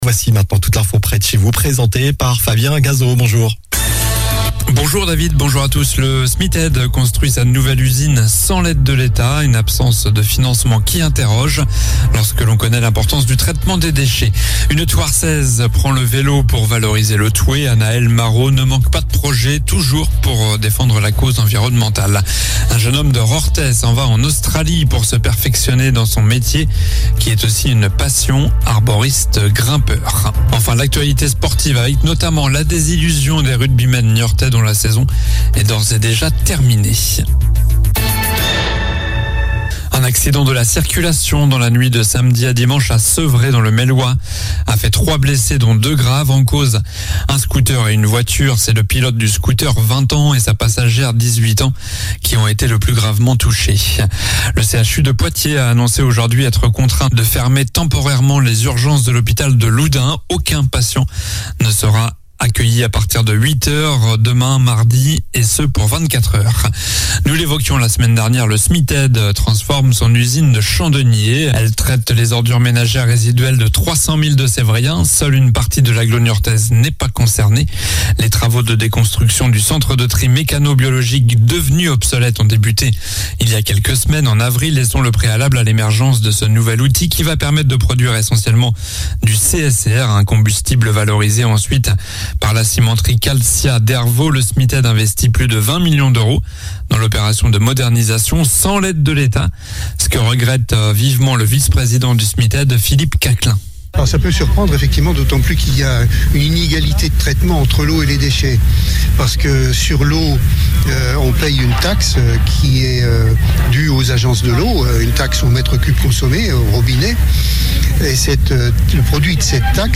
Journal du lundi 24 avril (midi)